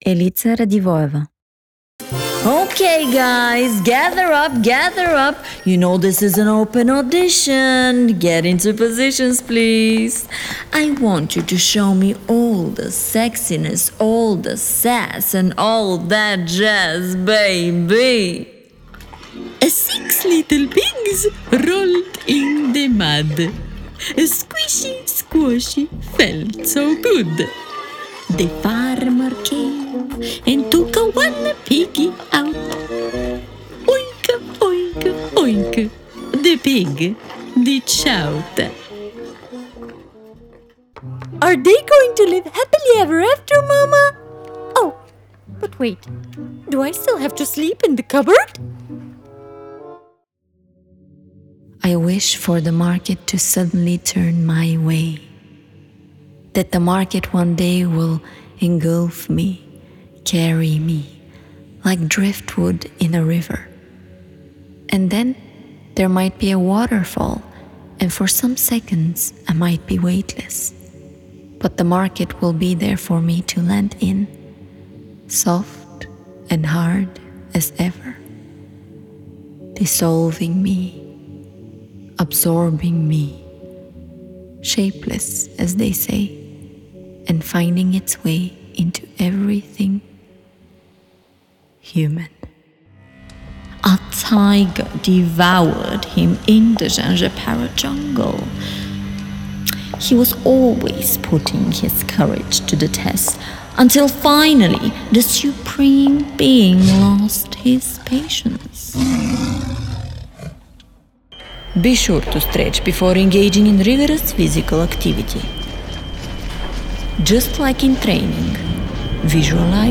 Character Voice Reel 2022